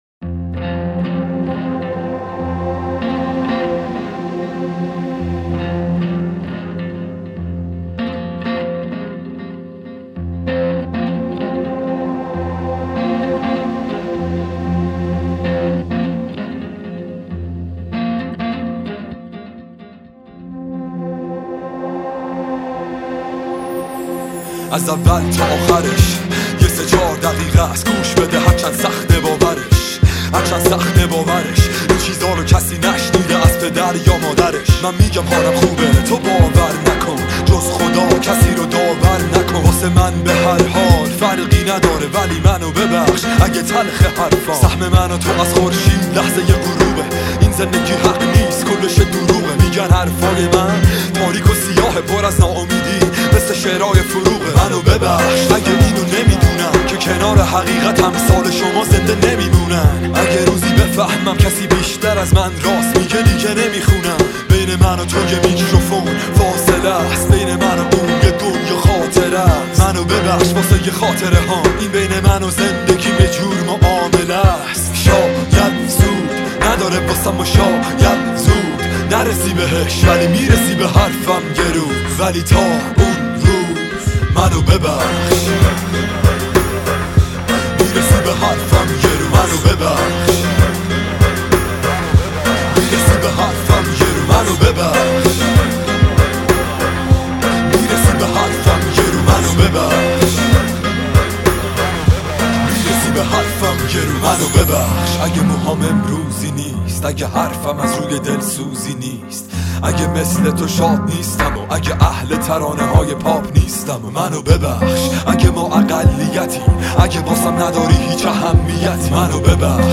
این قطعه واقعا بی نظیرِه و با سازه های سنتی نواخته شده